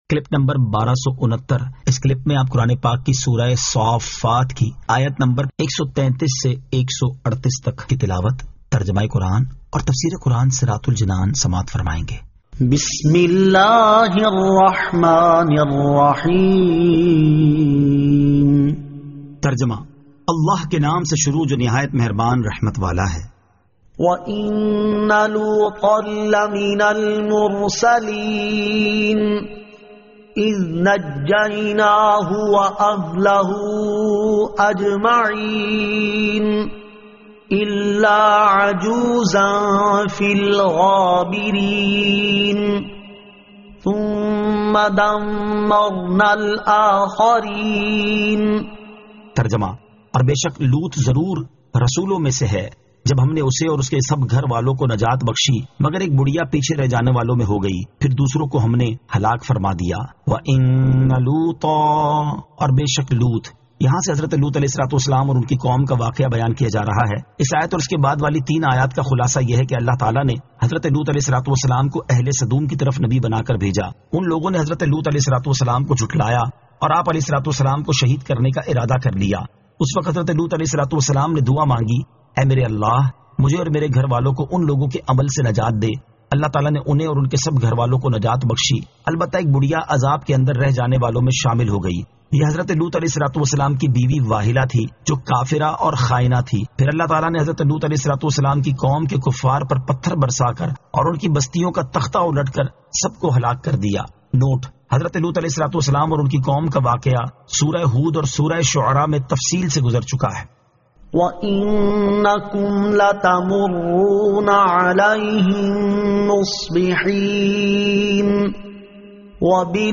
Surah As-Saaffat 133 To 138 Tilawat , Tarjama , Tafseer